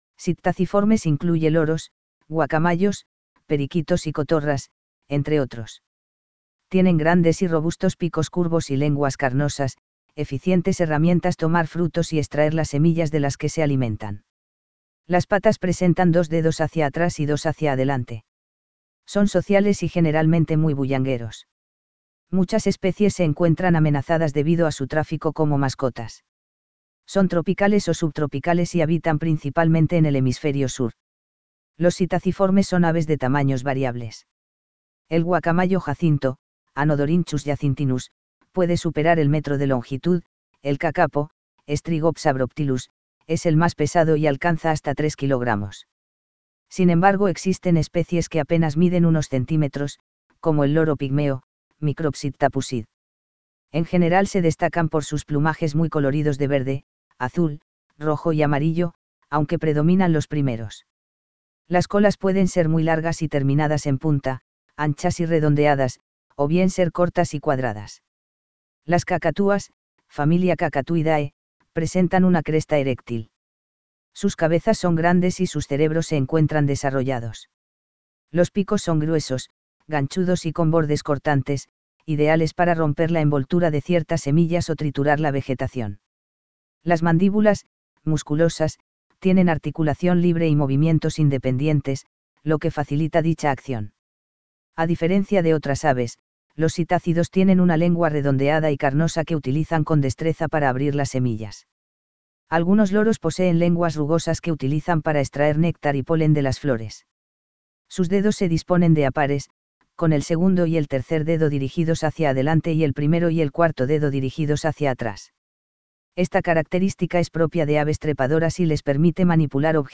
Son sociales y generalmente muy bullangueros.
Estas vocalizaciones mantienen los vínculos entre las parejas y refuerzan la cohesión de la bandada.
Cuando avistan a sus depredadores suelen emitir fuertes chillidos.